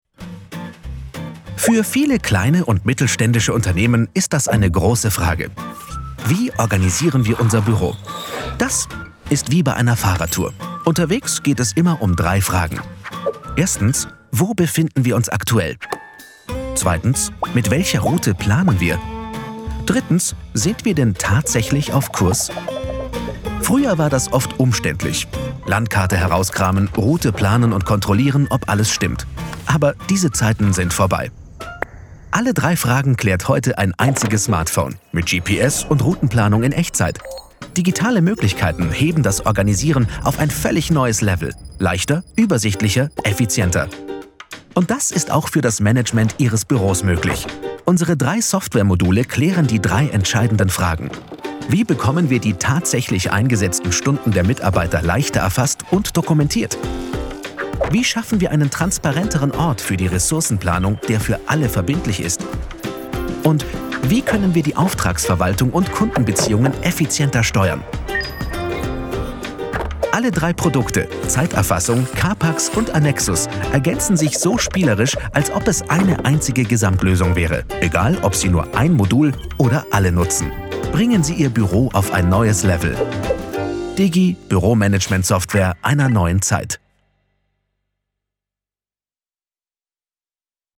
sehr variabel
Mittel minus (25-45)
Bayrisch